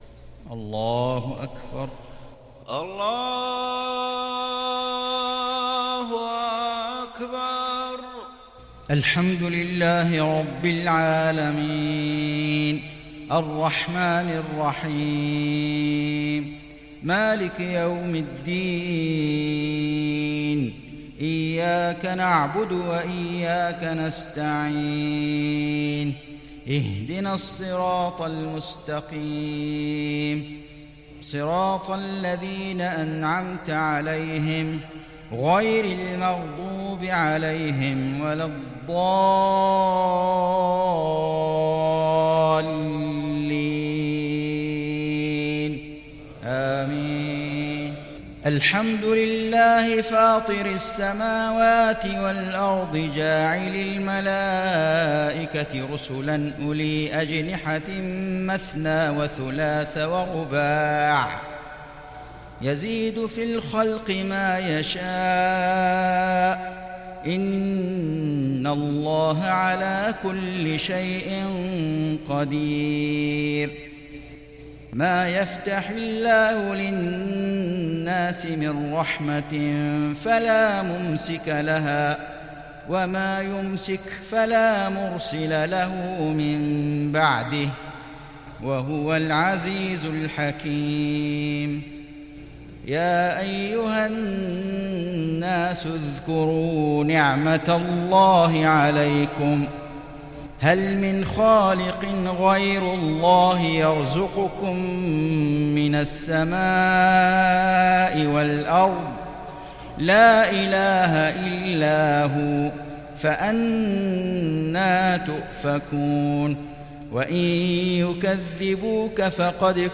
صلاة العشاء للشيخ فيصل غزاوي 25 جمادي الأول 1441 هـ
تِلَاوَات الْحَرَمَيْن .